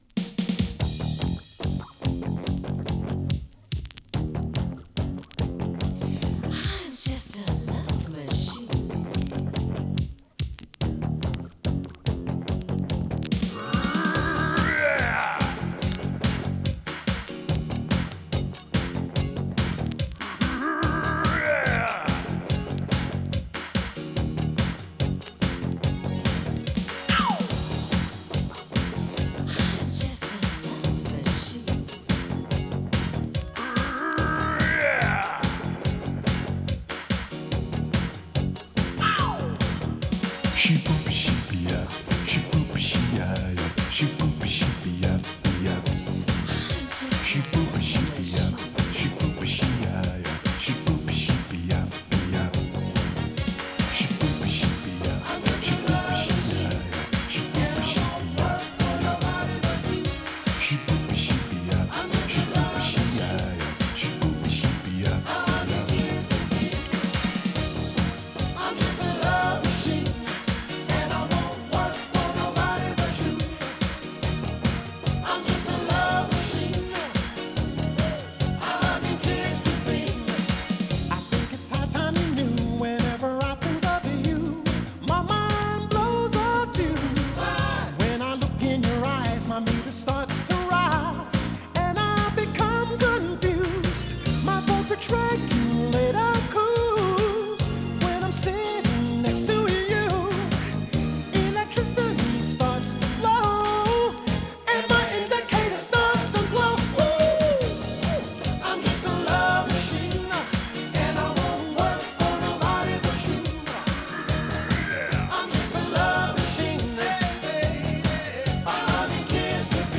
彼らのディスコ・ヒットをリメイクした艶やかでザワついたモダン・ダンサー！
[2version 12inch]＊音の薄い部分に軽いチリチリ・ノイズ。
♪Vocal (7.10)♪